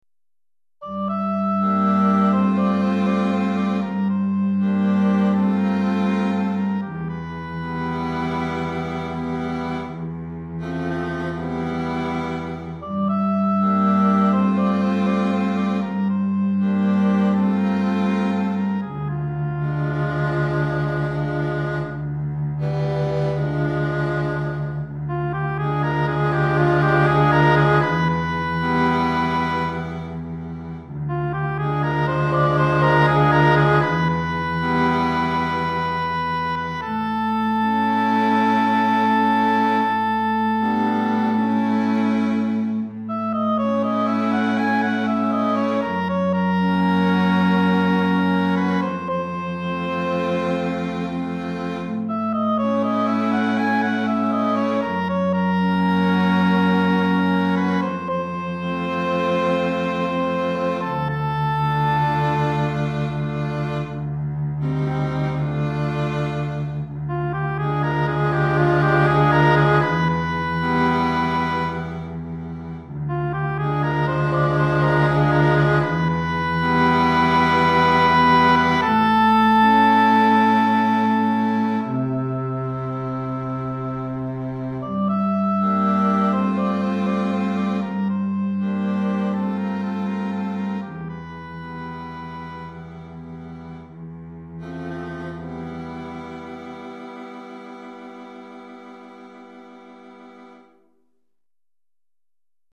Hautbois 2 Violons 1 Alto 1 Violoncelle